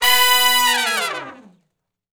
014 Long Falloff (B) unison.wav